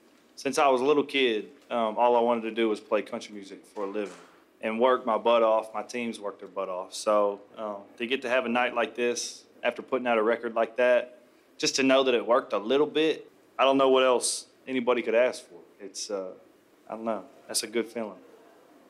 Audio / Backstage at Monday night’s ACM Awards, Parker McCollum says he’s realizing a dream come true.